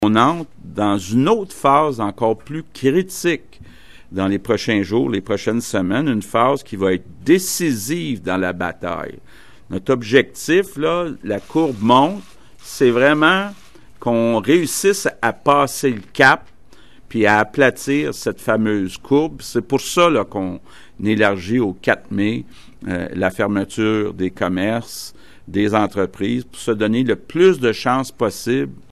Aux côtés du ministre de l’Économie, Pierre Fitzgibbon, le premier le ministre François Legault en a fait l’annonce, dimanche après-midi, lors de son point de presse quotidien.
Écouter le premier ministre Legault: